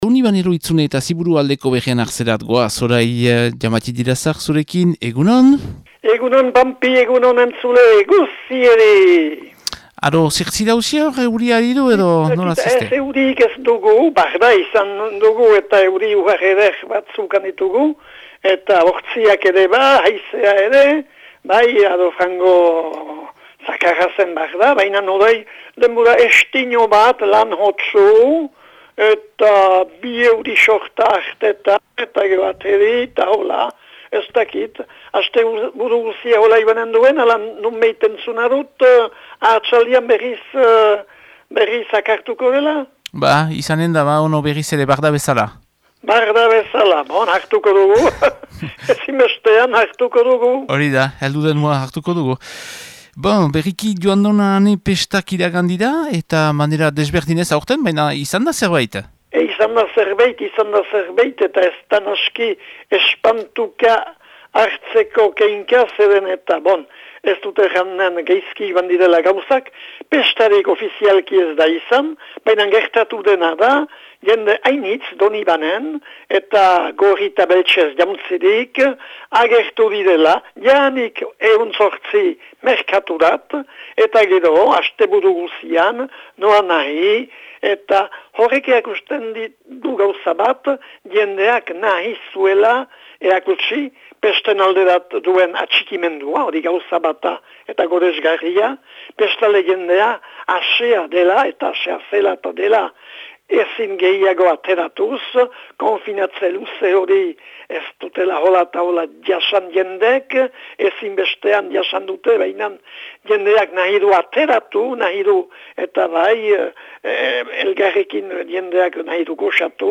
laguntzailearen berriak.